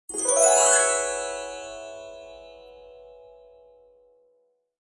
真实的 "神秘的风铃 "过渡特效
描述：你的定型风铃音效。
我录制了一个非常快速的录音，有人击中了一些管弦乐的钟声，将它放慢了200％以上，并在后期制作中加入了一些谐波激励和均衡。
标签： 梦想 风铃 管弦乐 效果 吉普赛人 神秘 效果 过渡 神秘 FX 幻想 SFX 风铃 风铃 声音
声道立体声